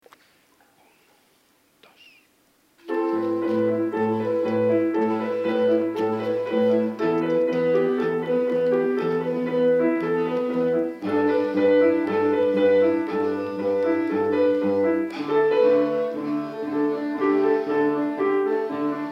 - Tonalidad: Mi menor
Interpretaciones en directo.
5º ritmo semicorcheas
5o_ritmo-semicorcheas.MP3